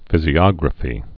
(fĭzē-ŏgrə-fē)